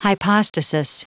Prononciation du mot hypostasis en anglais (fichier audio)
Prononciation du mot : hypostasis